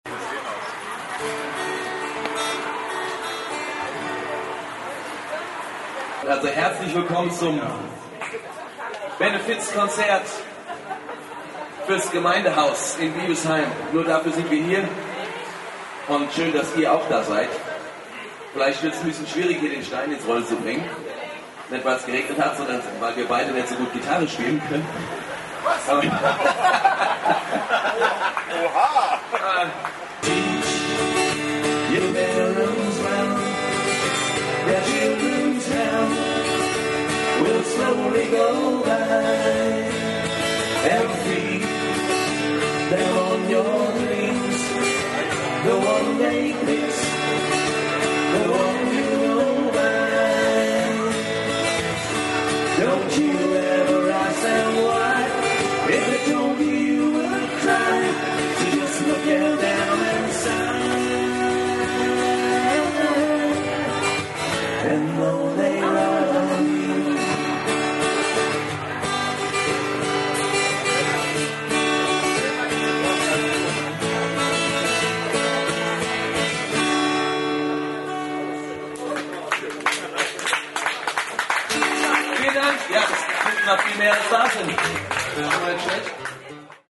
Benefiz-Konzert
Take 5 trotz heftigem Regenschauer live vor'm Gemeindehaus
Seit Langem wurde mal wieder "unplugged" eröffnet